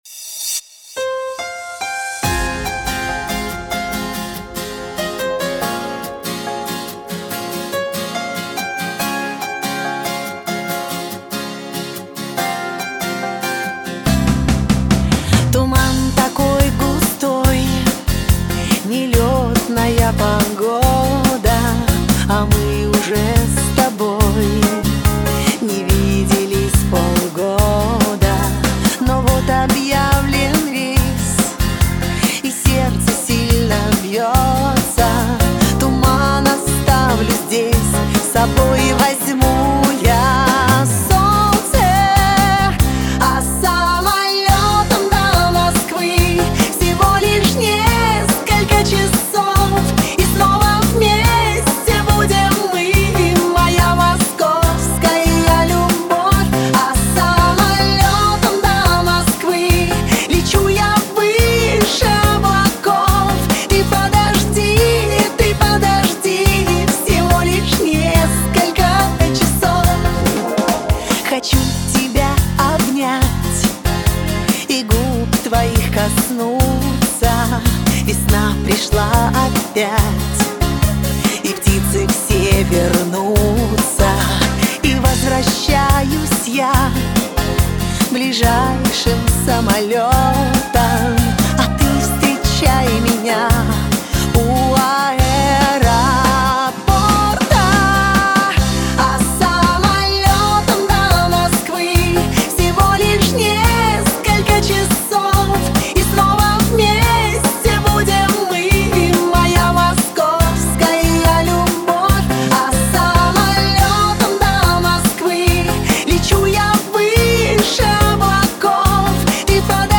Всі мінусовки жанру Pop
Плюсовий запис